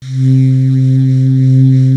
55AF-SAX01-C.wav